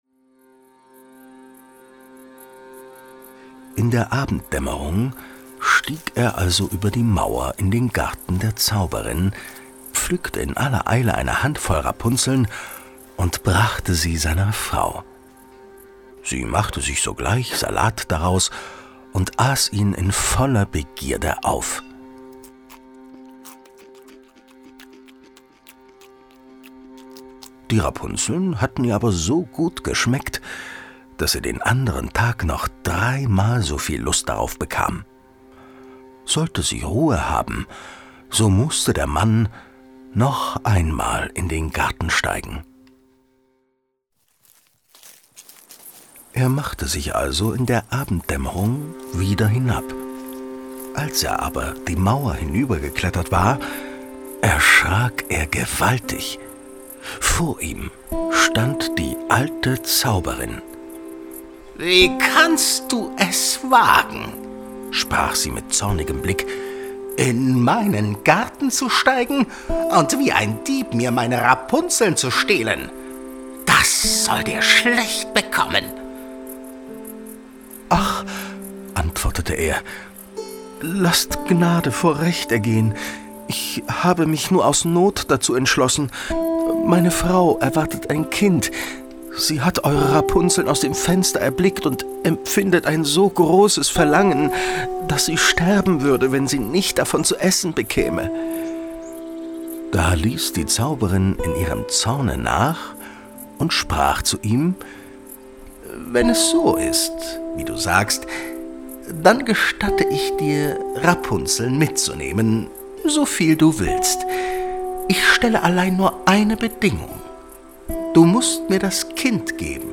Ein musikalisches Hörbuch mit Liedern
Sprecher und Sänger